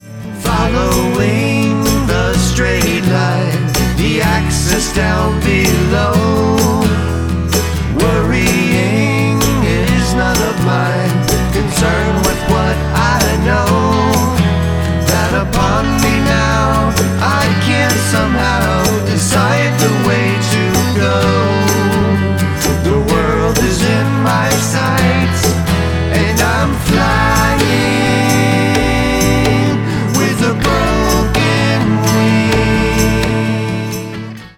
acoustic live in the studio